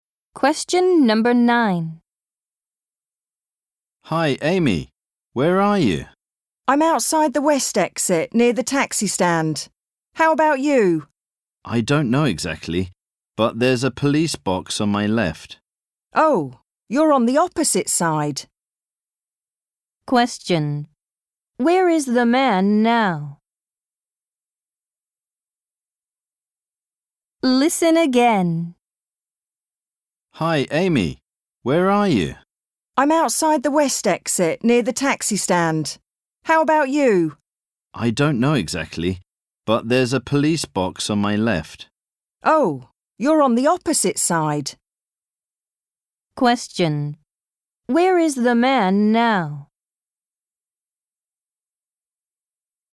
○共通テストの出題音声の大半を占める米英の話者の発話に慣れることを第一と考え，音声はアメリカ（北米）英語とイギリス英語で収録。
第2問　問9 （イギリス英語）